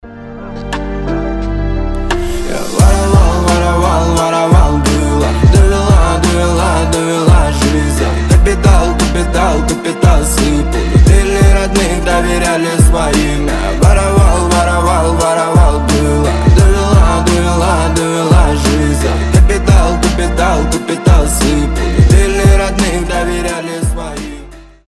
• Качество: 320, Stereo
грустные